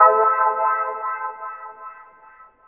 PIANO HIT2-L.wav